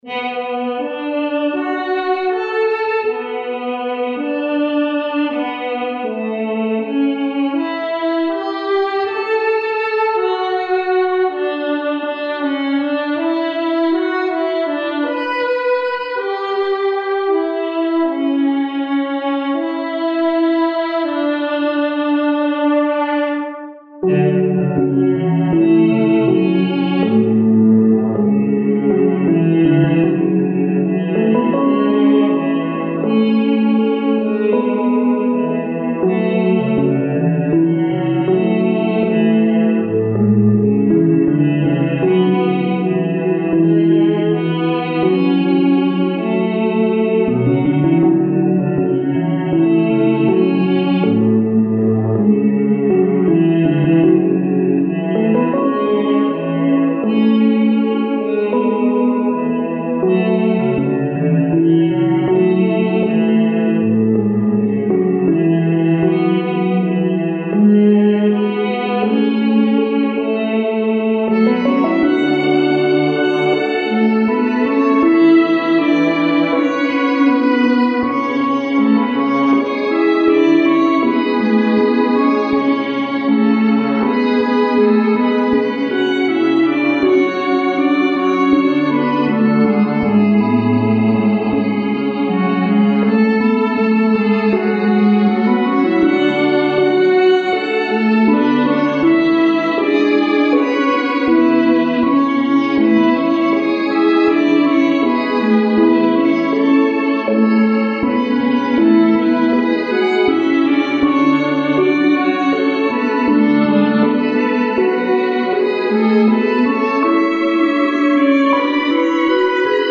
それぞれ１ループの音源です♪
イントロあり